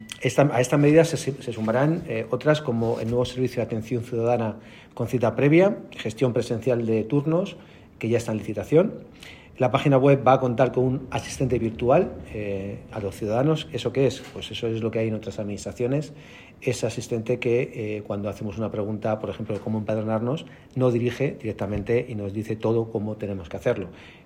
Así lo ha anunciado durante la rueda de prensa celebraba, esta mañana, para valorar los asuntos tratados en la Junta de Gobierno de la Ciudad de Toledo, celebrada el martes, 29 de agosto.
Cortes de voz
corte-de-voz-3-juan-jose-alcalde.mp3